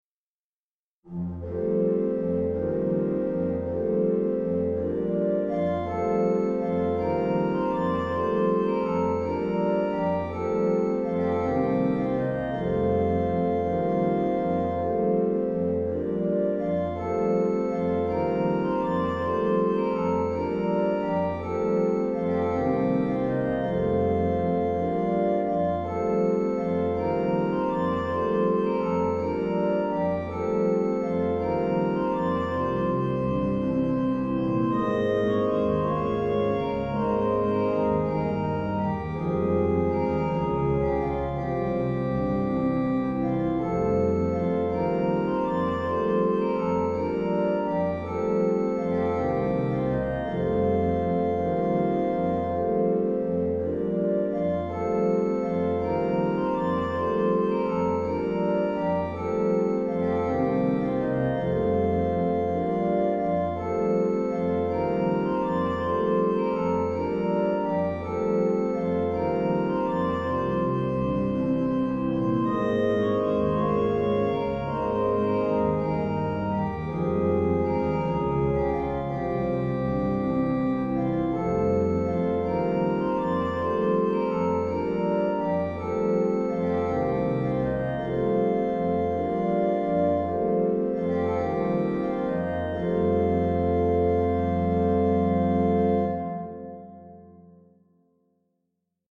New hymn tune "Glenmoore" setting of a new text by John A. Dalles, appropriate for an anniversary celebration.